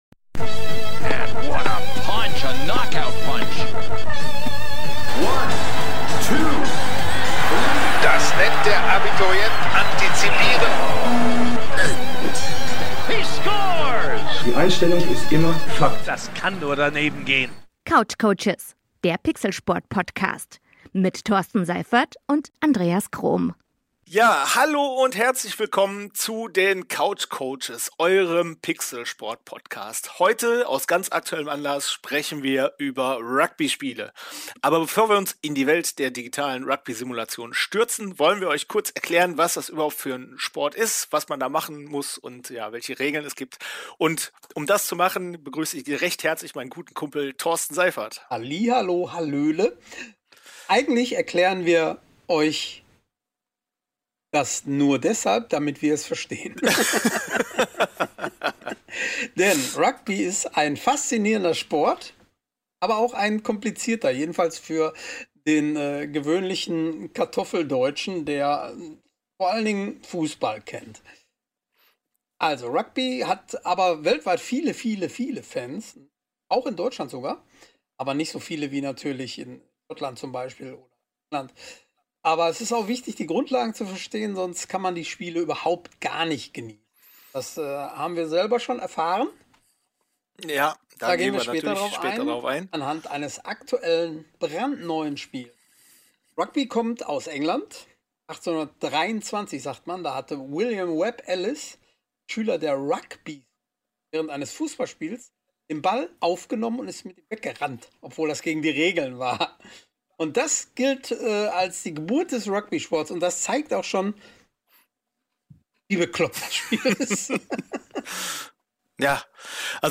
Doch unsere CoachCoaches bekommen fachlich solide Hilfe von einem Studiogast. Danach können sie unfallfrei über die Geschichte des Rugby im Pixelsport und der aktuellen Ausgabe von RUGBY 25 von Big Ant quatschen.